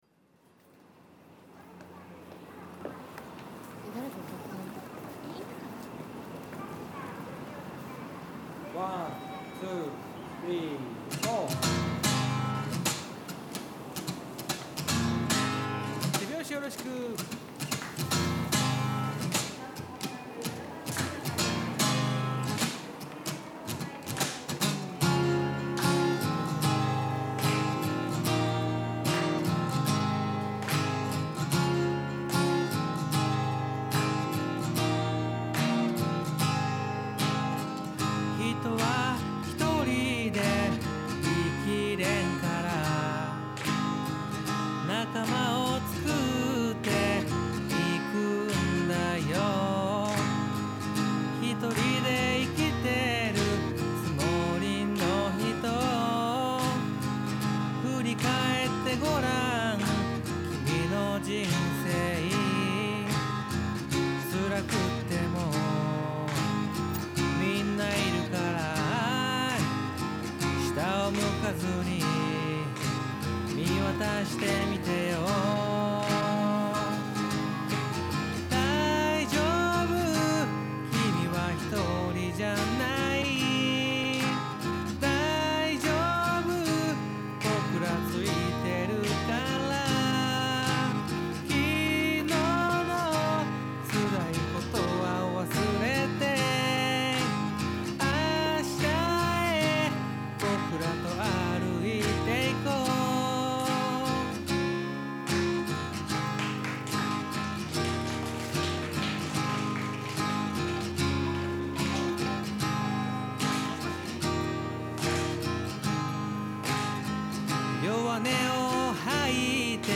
Vo＆AG